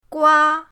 gua1.mp3